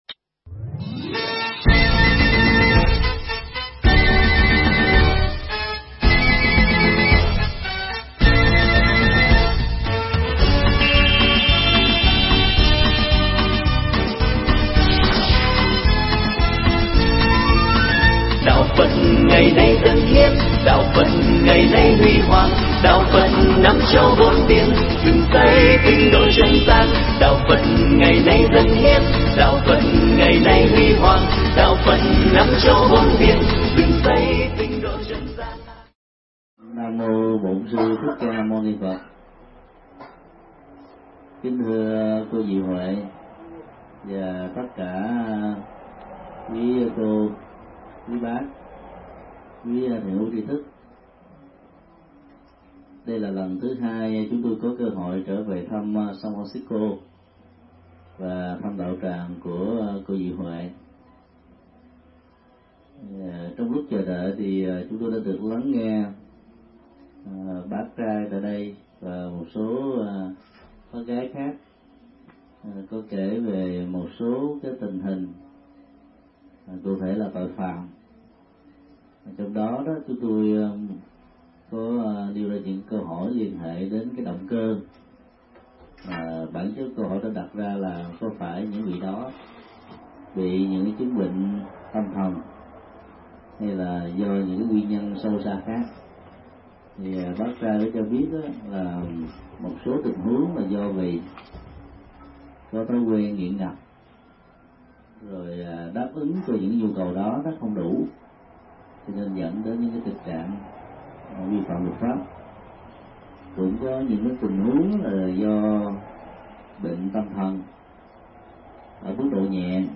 Tải mp3 Bài giảng Tâm và hành vi do thầy Thích Nhật Từ Giảng tại đạo tràng Diệu Huệ, San Francisco, USA, ngày 3 tháng 7 năm 2007